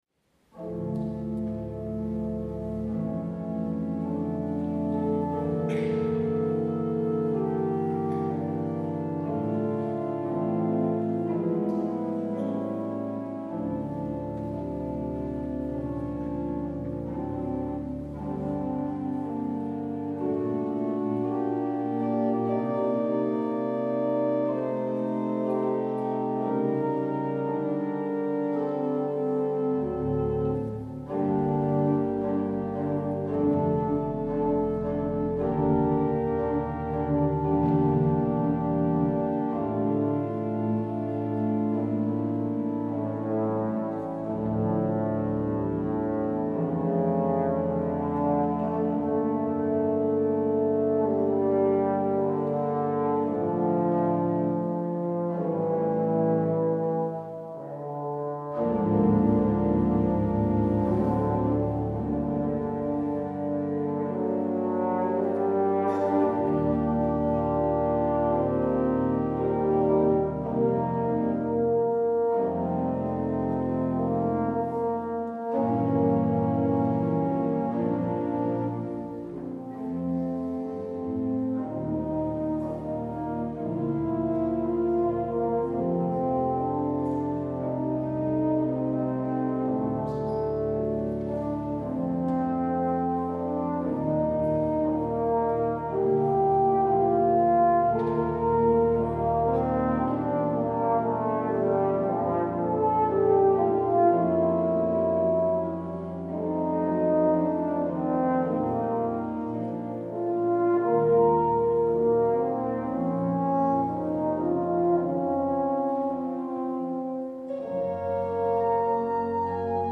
Vielseitiger Horn- und Orgelsound
Horn
Orgel